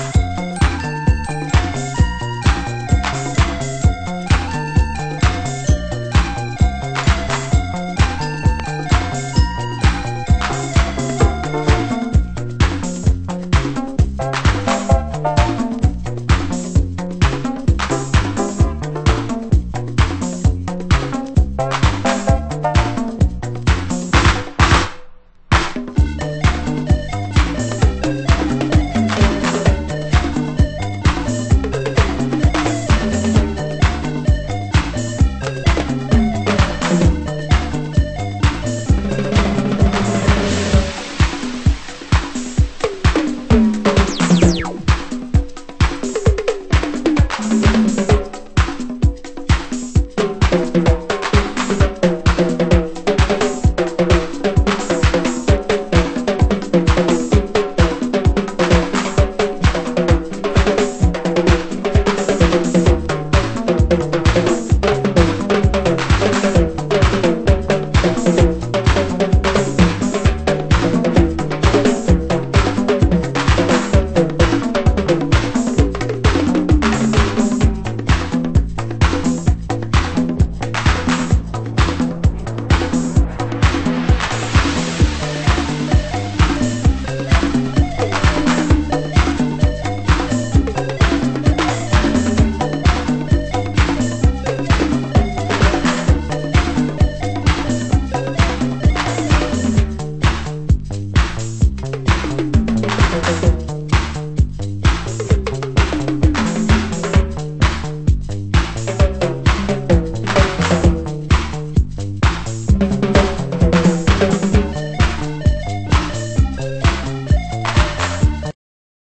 90'S HOUSE UK/EU
盤質：少しチリパチノイズ有